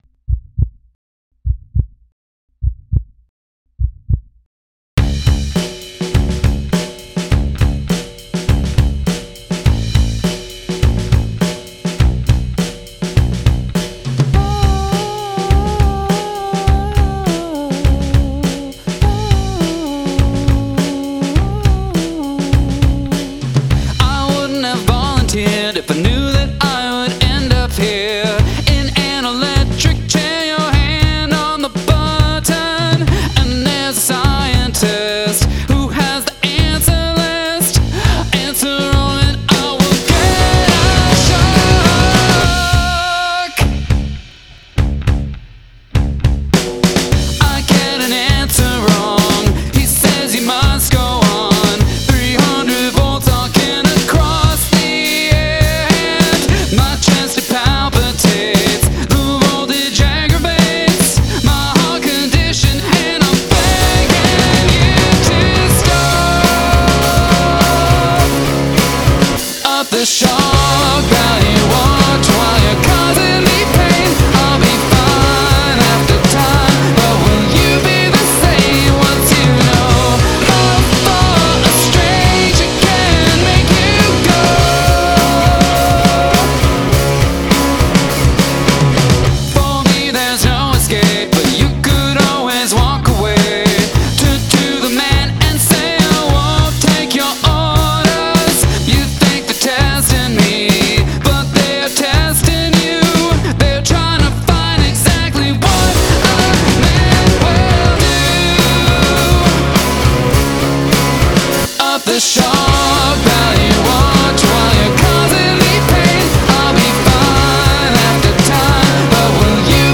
I particularly like the vocal melody in the chorus.